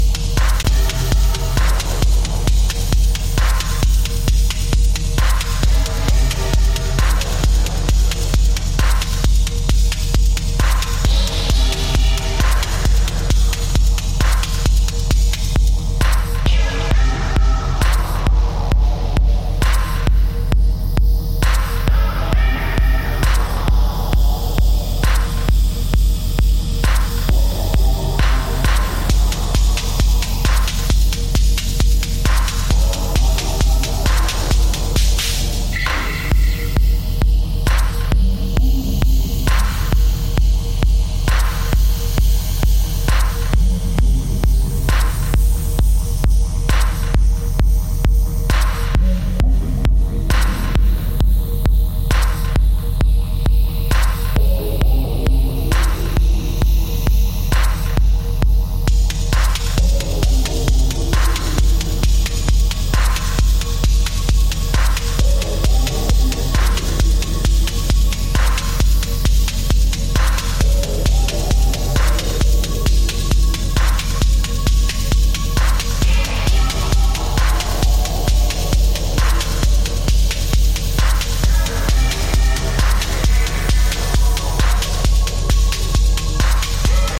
Deep percussive techno drones